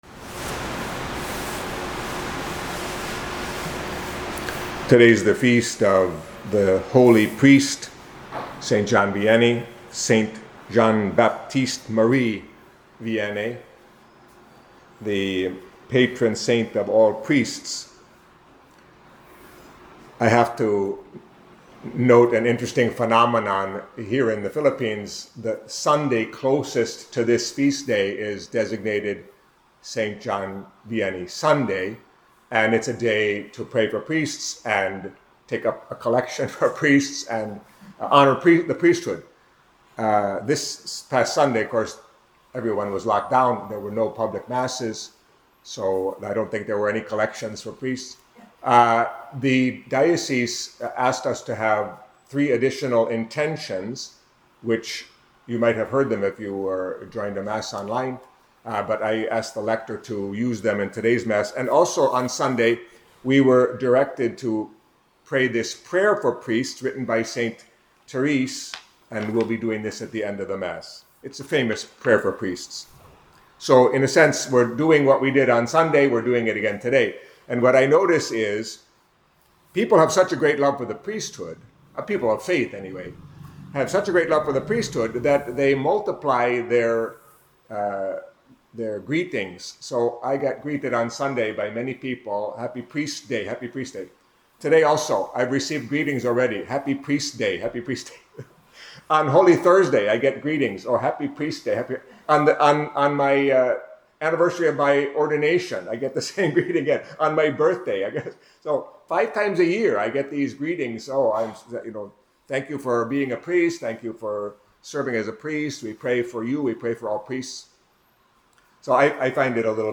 Catholic Mass homily for Wednesday of the 18th Week in Ordinary Time